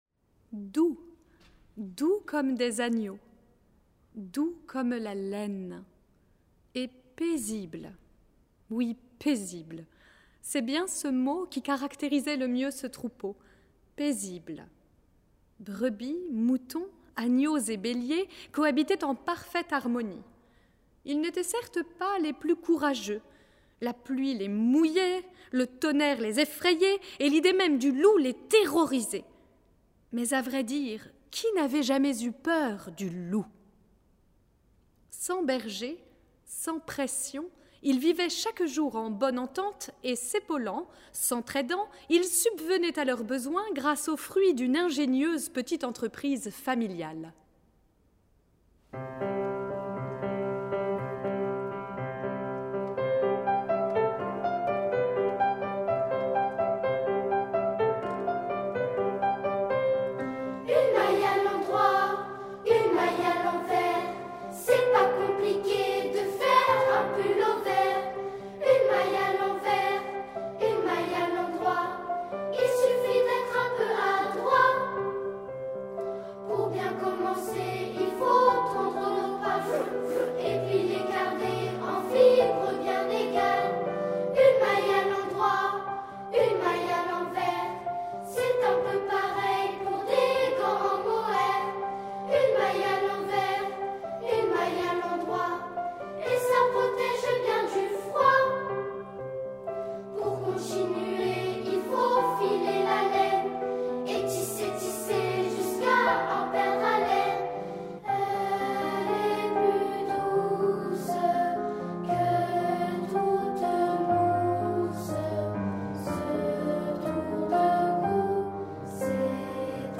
Genre :  ChansonComptine
Style :  Avec accompagnement
Enregistrement piano et voix